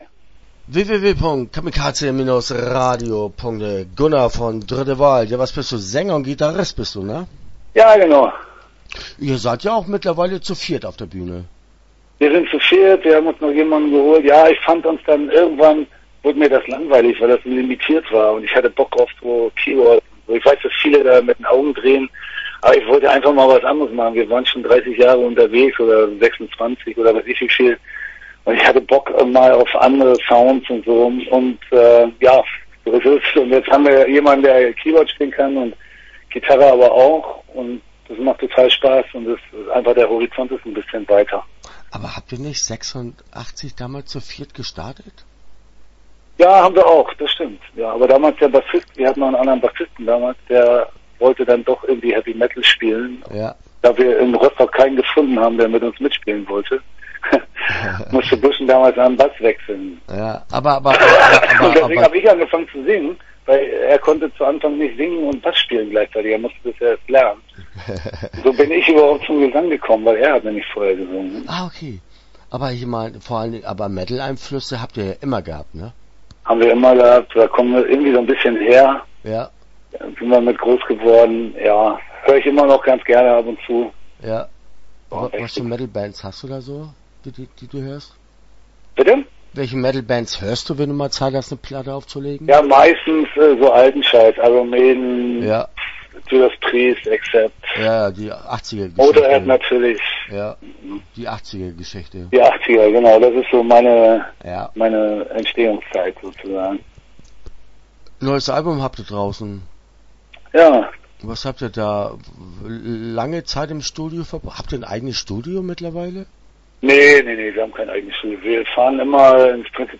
Dritte Wahl - Interview Teil 1 (8:11)